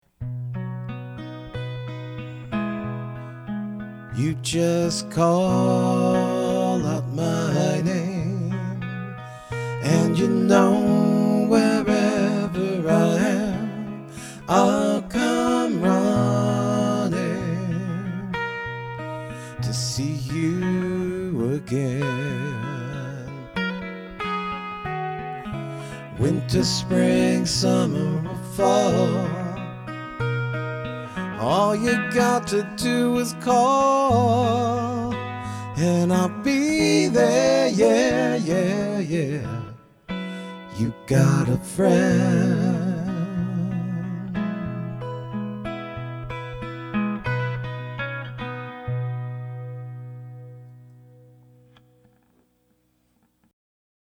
Here are a few example clips I recorded direct into my DAW: